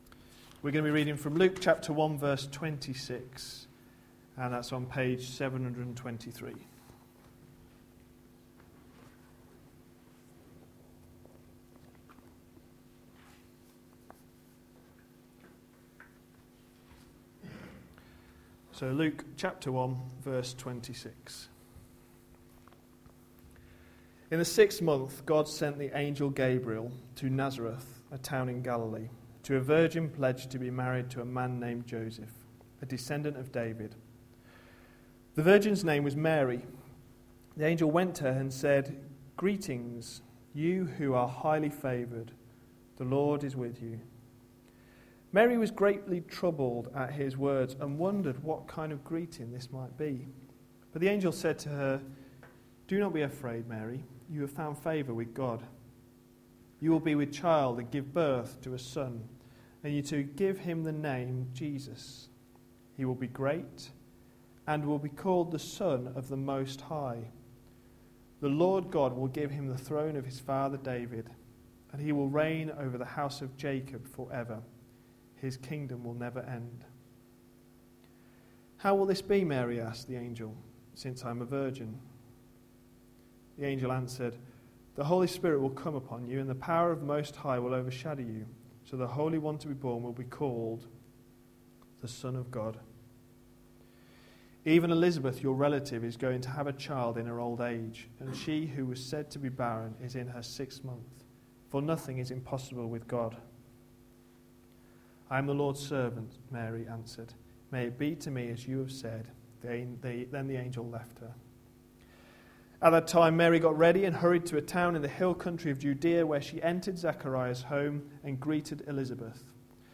A sermon preached on 11th December, 2011.